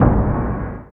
50 KIK NSE-R.wav